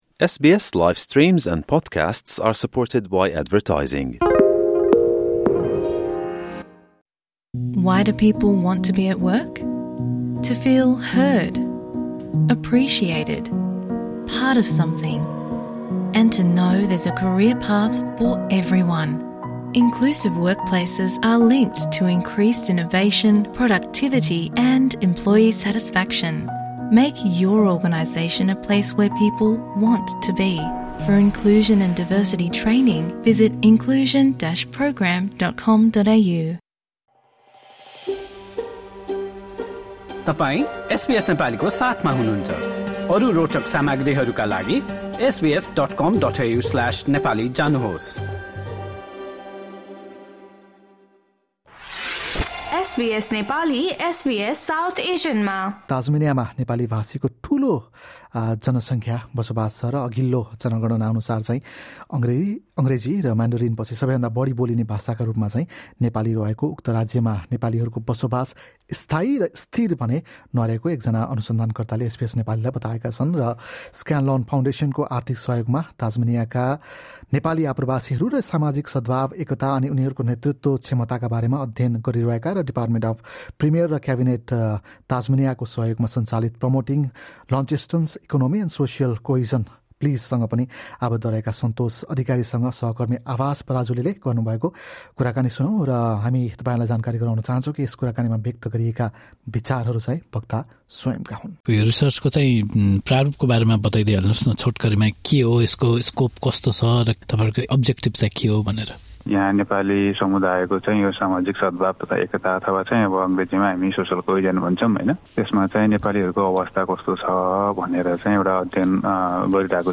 एसबीएस नेपालीले गरेको कुराकानी सुन्नुहोस्।